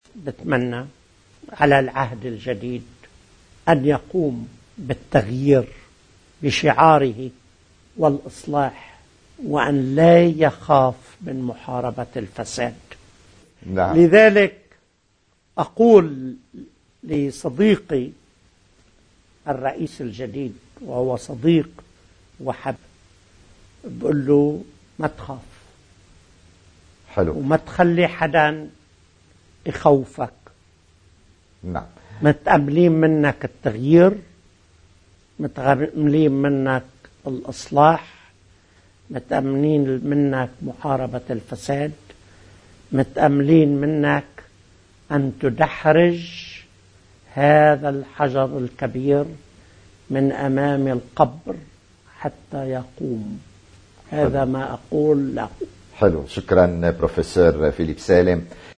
مقتطف من حديث البروفسور فيليب سالم ضمن برنامج “تحديات” على قناة “تيلي لوميار”